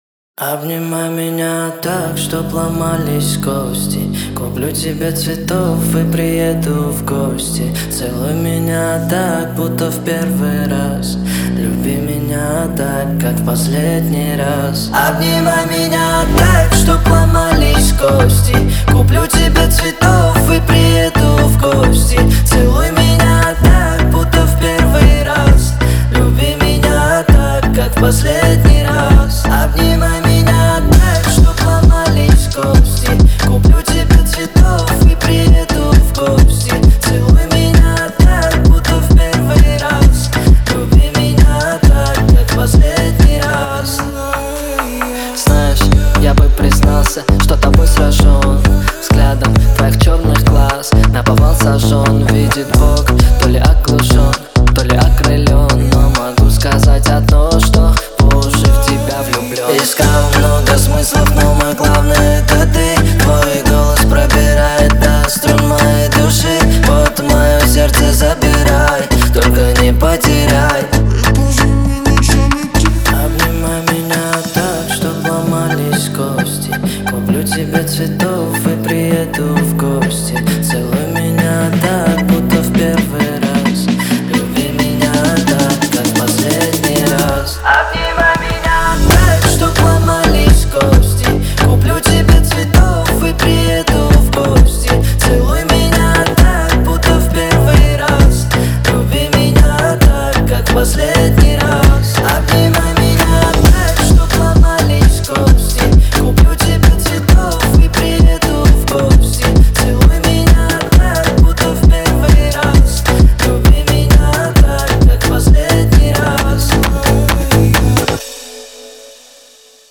Плейлисты: Phonk, Funk, Фонк (2025)
Фонк музыка 2025